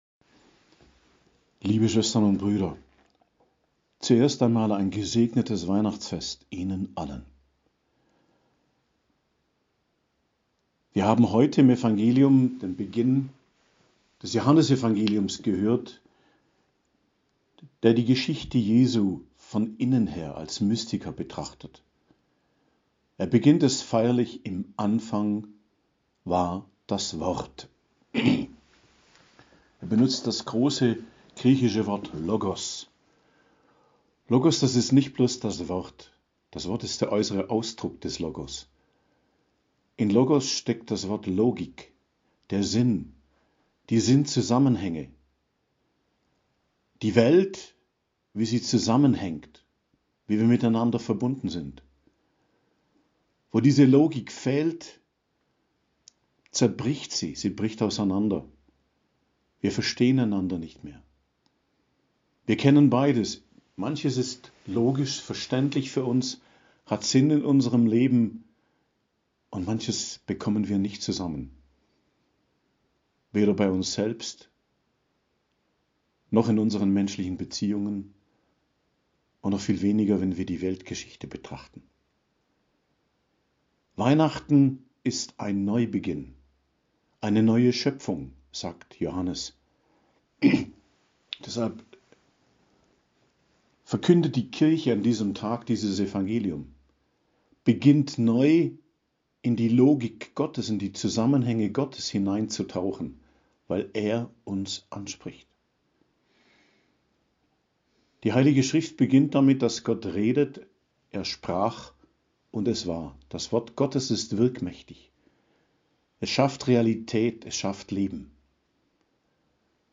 Predigt zu Weihnachten, 25.12.2022